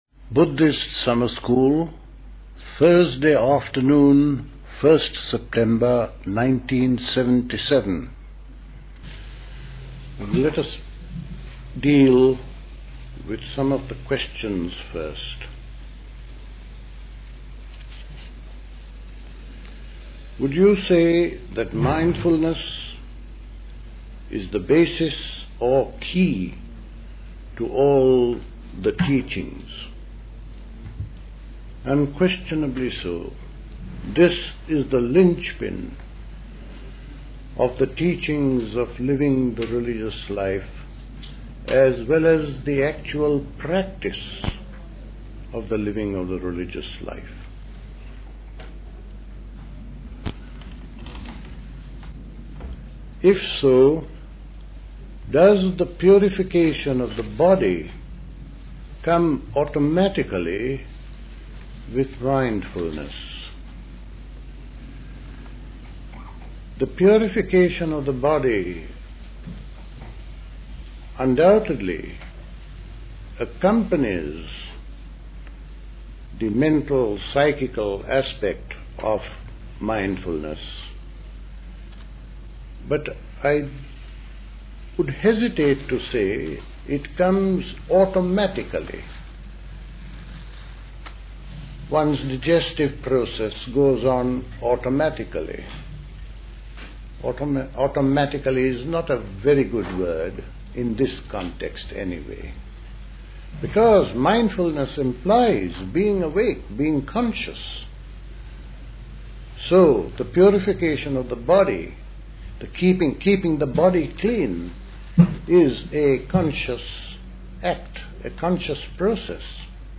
A talk
at High Leigh Conference Centre, Hoddesdon, Hertfordshire on 1st September 1977